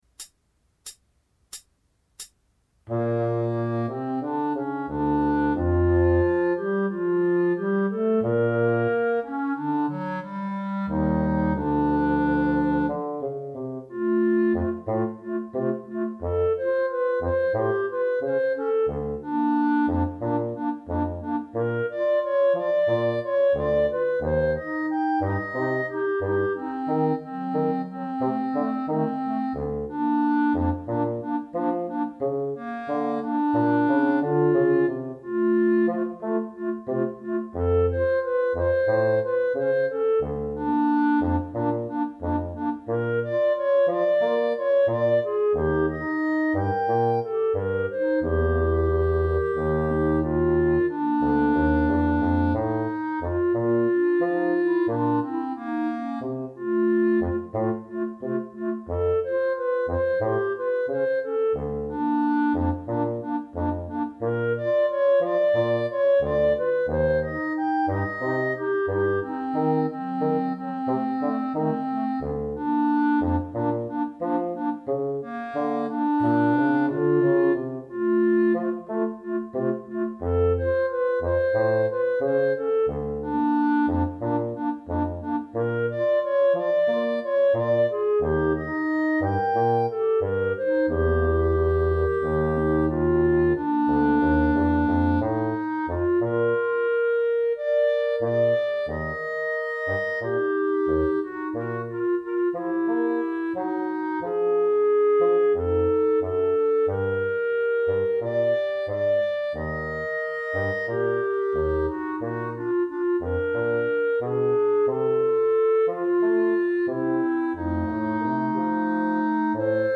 minus Clarinet 1